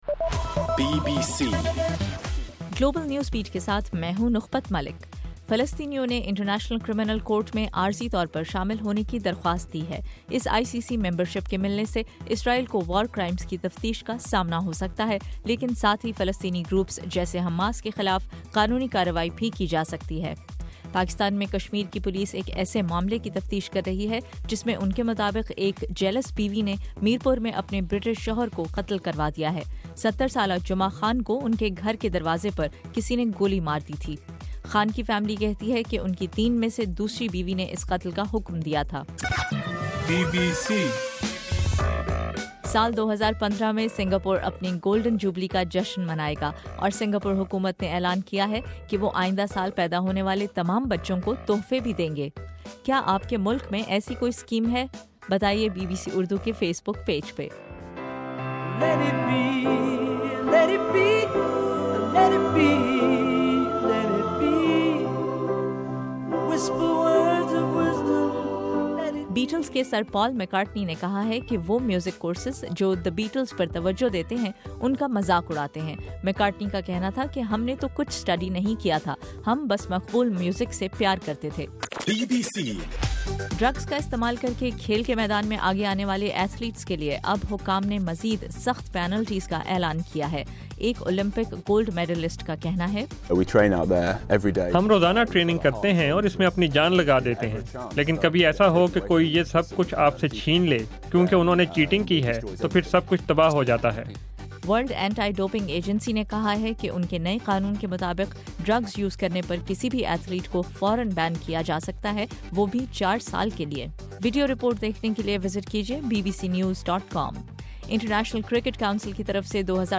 جنوری 1: صبح 1 بجے کا گلوبل نیوز بیٹ بُلیٹن